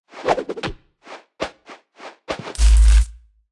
Media:Sfx_Anim_Ultra_Jessie.wav 动作音效 anim 在广场点击初级、经典、高手和顶尖形态或者查看其技能时触发动作的音效